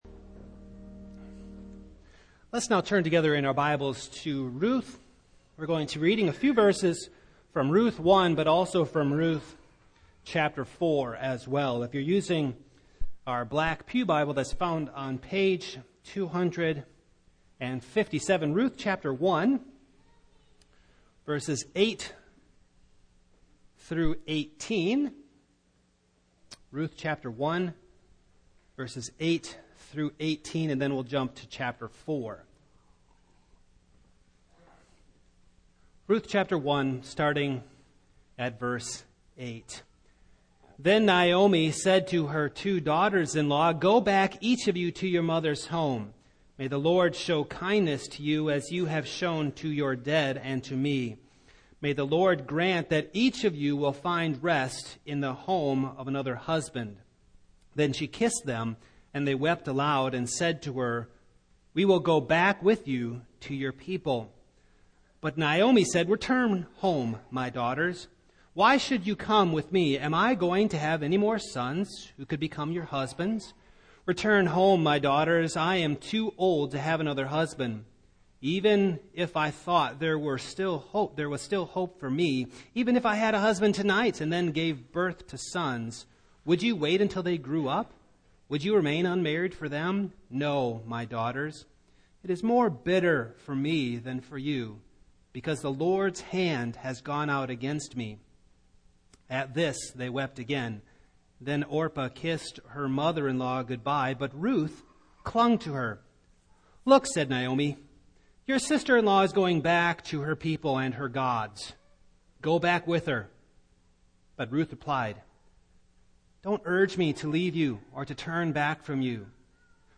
Series: Single Sermons
Service Type: Morning